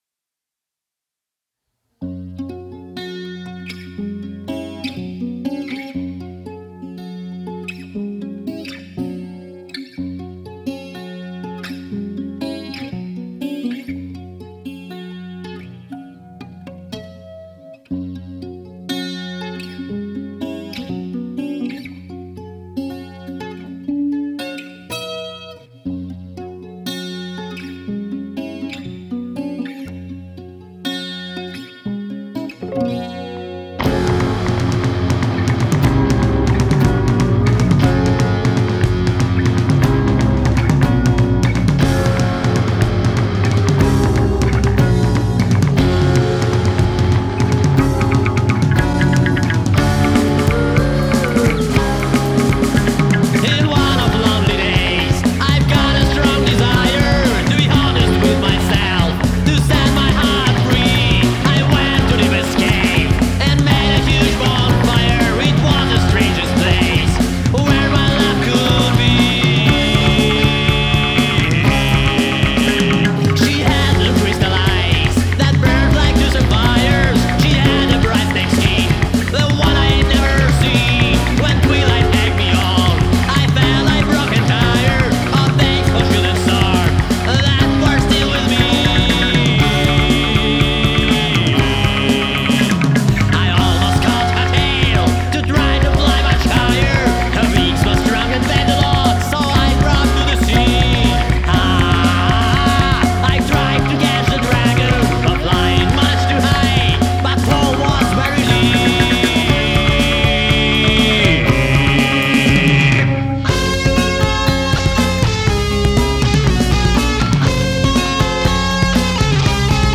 Интересно в целом впечатление, оценка хриплого вокала, а также аранжировки.